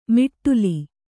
♪ miṭṭuli